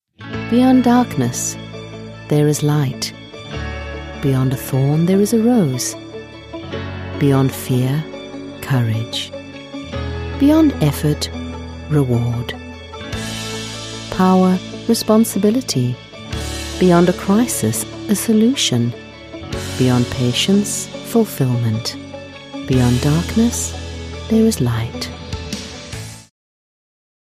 Mi acento inglés neutro funciona bien en los mercados internacionales.
Mi voz es natural y amigable, pero resonante y autoritaria. A mis clientes también les encanta mi voz seductora.
Micrófono Audio Technica AT2020